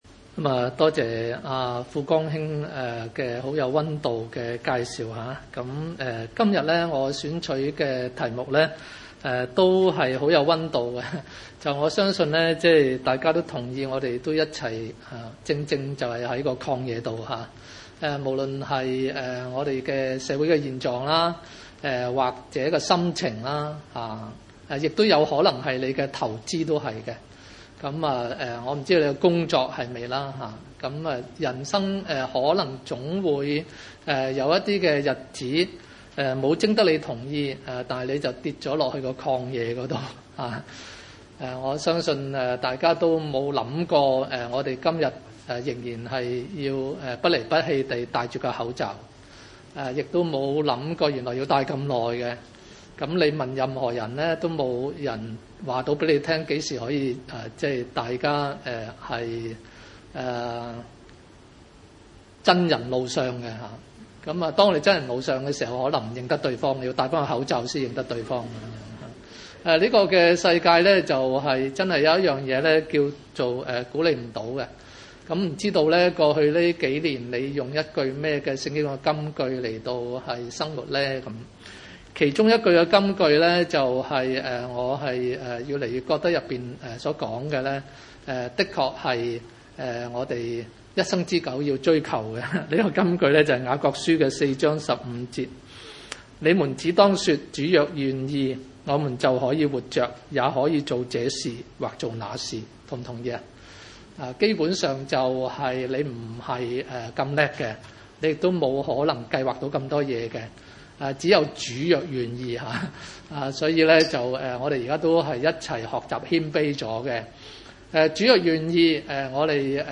出埃及記 13：17-22 崇拜類別: 主日午堂崇拜 17 法 老 容 百 姓 去 的 時 候 ， 非 利 士 地 的 道 路 雖 近 ， 神 卻 不 領 他 們 從 那 裡 走 ； 因 為 神 說 ： 恐 怕 百 姓 遇 見 打 仗 後 悔 ， 就 回 埃 及 去 。